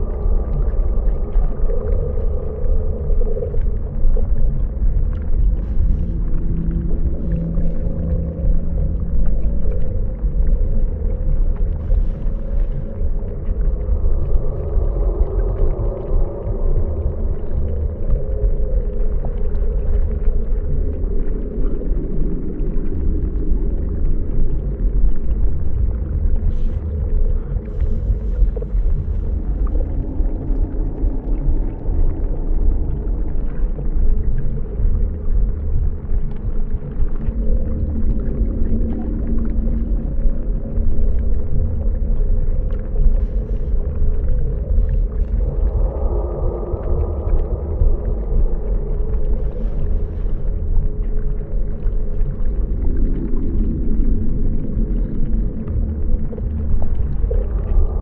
Sfx_amb_treespire_ventgarden_outer_amb_01.ogg